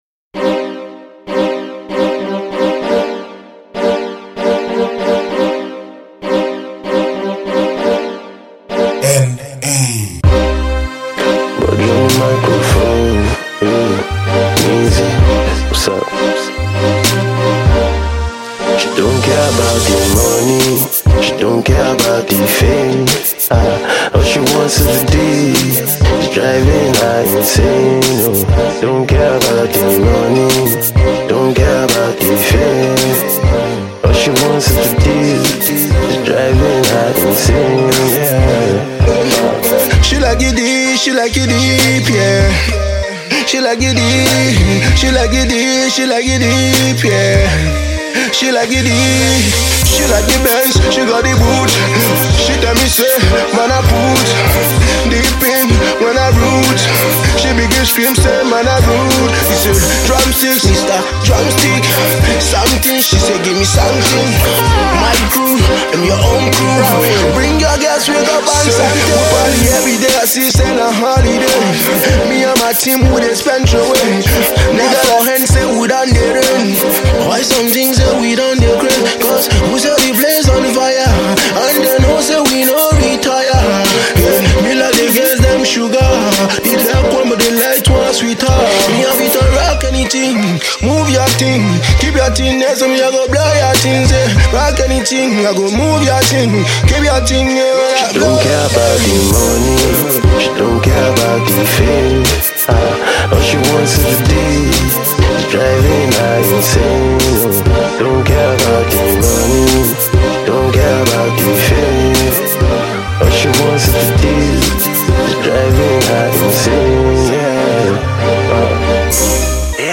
quick freestyle